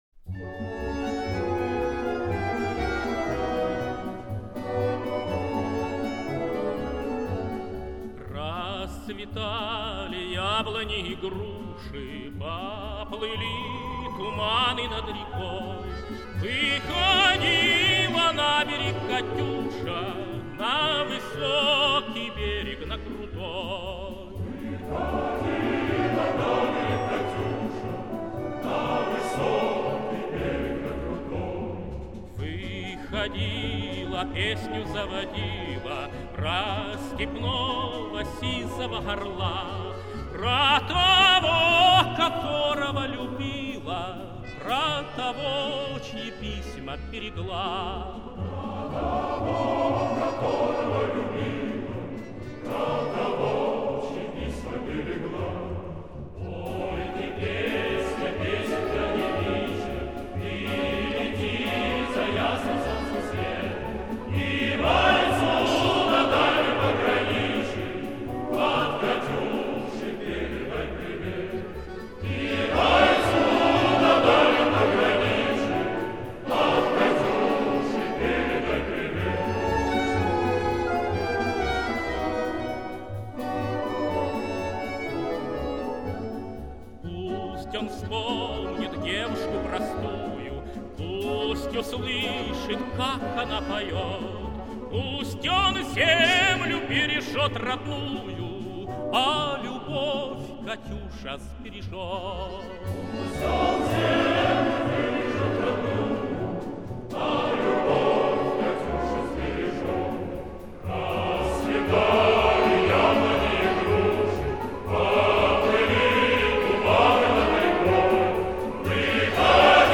в современном исполнении